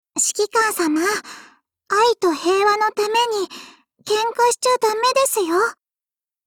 碧蓝航线:小光辉语音